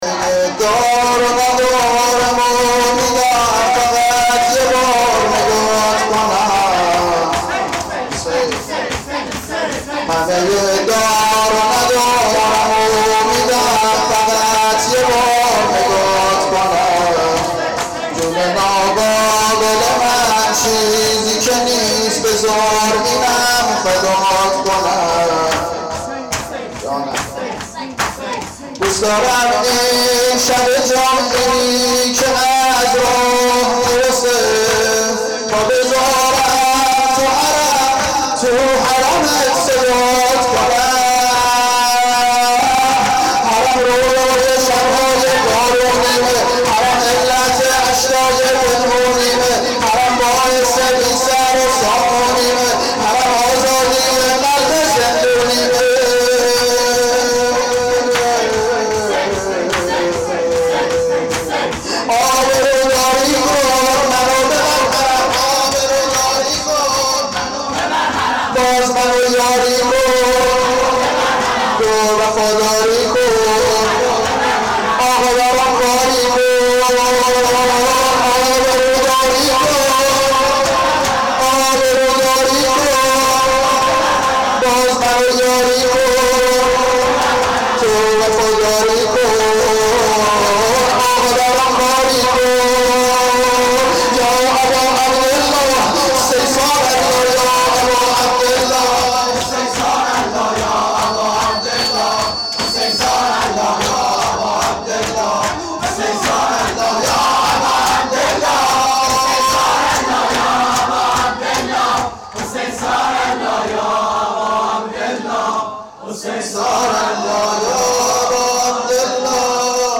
• مراسم سینه زنی شب نهم محرم هیئت روضه الحسین
شور-همه-دار-و-ندارمو-میدم-شب9.mp3